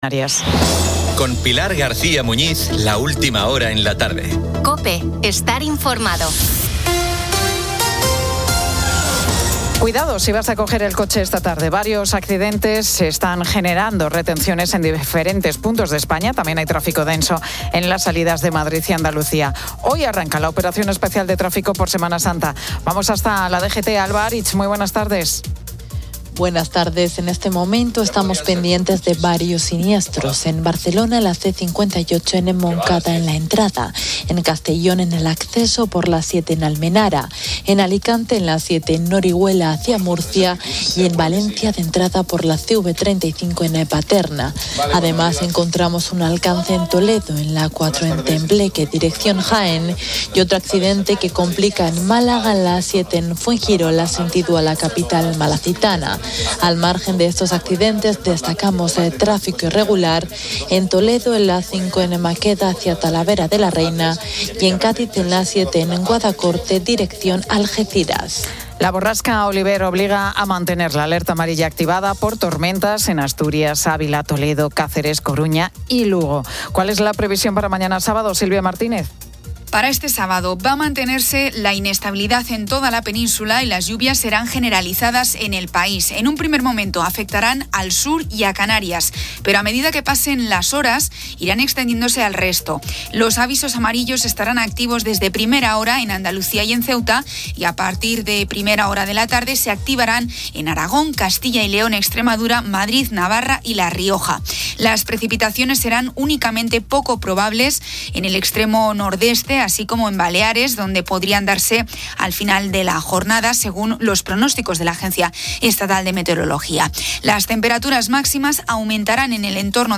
La Tarde 18:00H | 11 ABR 2025 | La Tarde Pilar García Muñiz entrevista a Leire Martínez con motivo de su primer single en solitario tras La Oreja de Van Gogh. También descubre los distintos tipos de torrijas, desde las más clásicas hasta las más innovadoras.